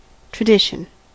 Ääntäminen
US
IPA : /trəˈdɪʃ.ən/